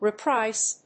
/riˈpraɪs(米国英語), ri:ˈpraɪs(英国英語)/